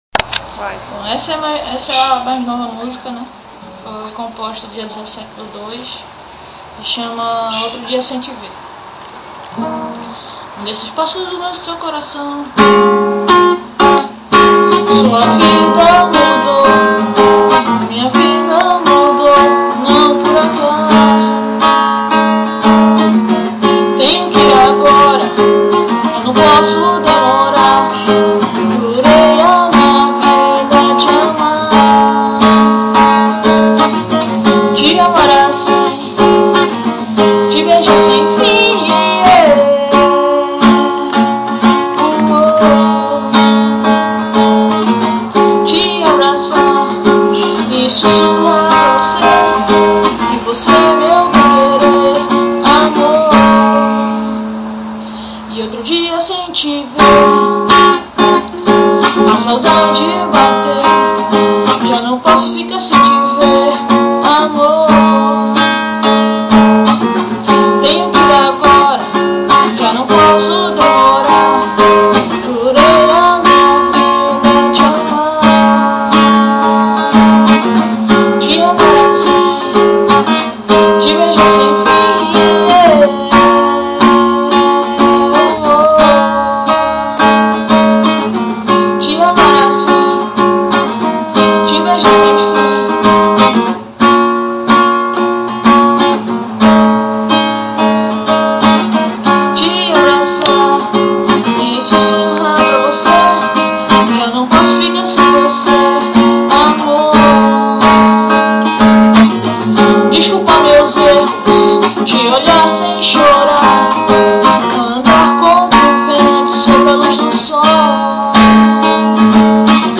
EstiloEmocore